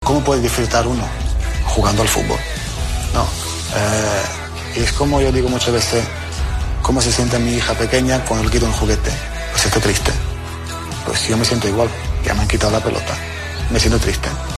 El jugador croata del Barça confiesa  su tristeza en 'Universo Valdano': "¿Cómo se sienta mi hija cuando le quitan un juguete? Triste. Yo me siento igual".
La entrevista se emite íntegra este jueves 14 de noviembre.